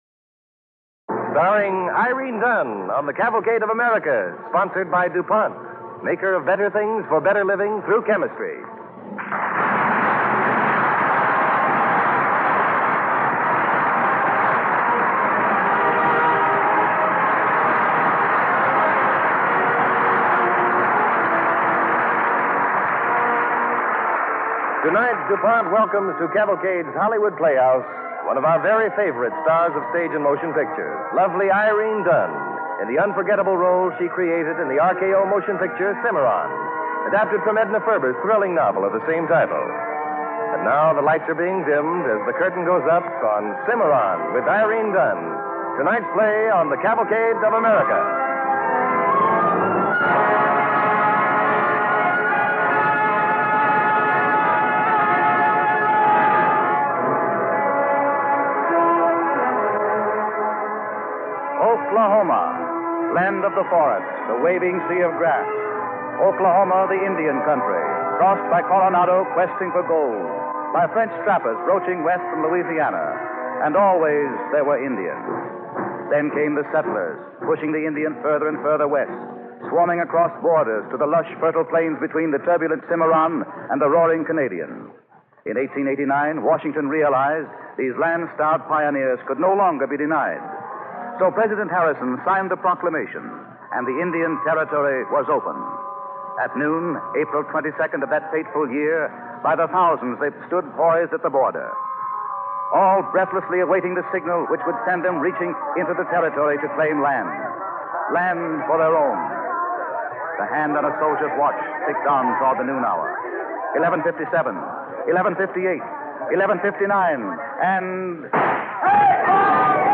Cavalcade of America Radio Program Cimarron, starring Irene Dunne and Gale Gordon, December 1, 1941